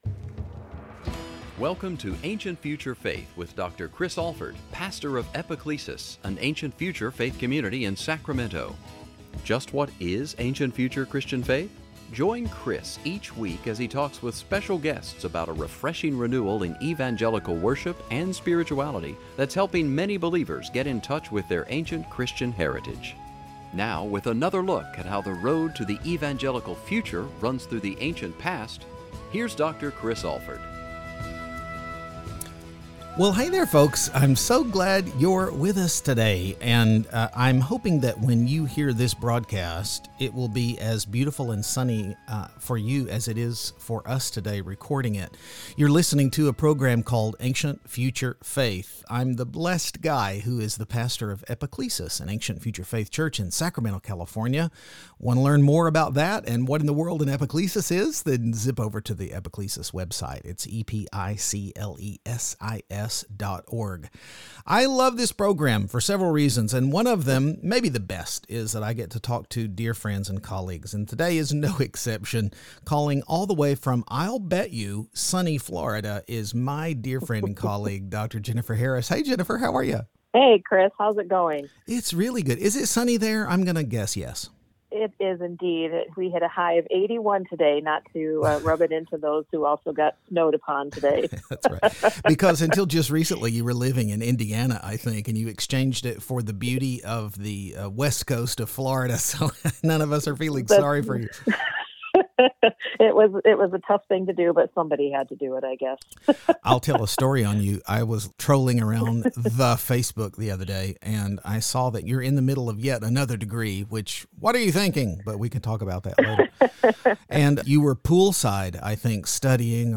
How can we be hospitable, how can we "be present," in times like these? And, by the way, is there such a thing as "multitasking"? Join us for a conversation about true, Christian hospitality.